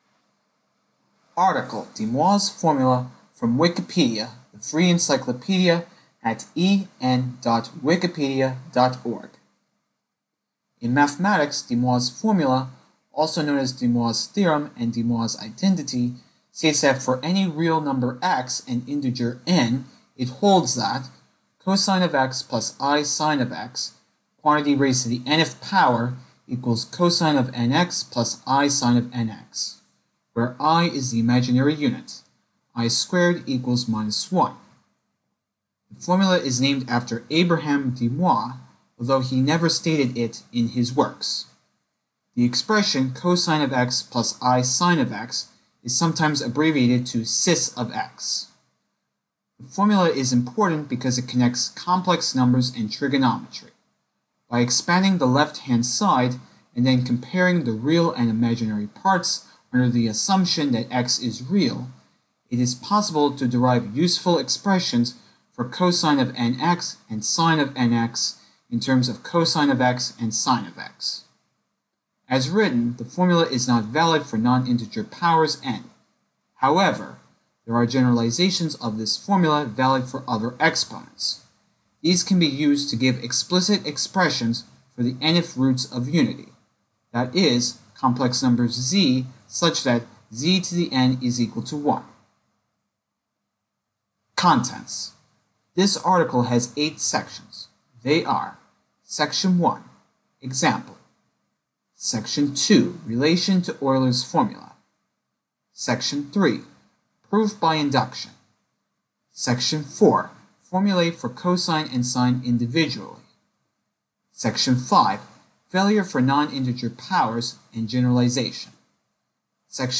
English spoken article